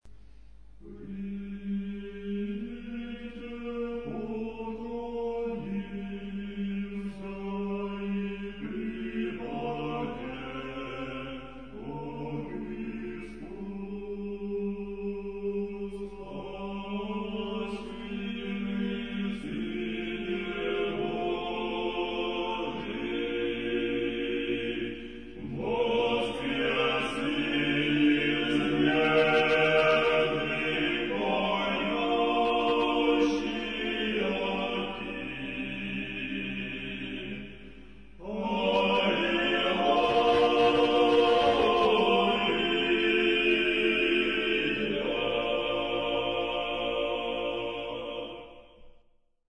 а освящение храма святого Иоанна Лествичника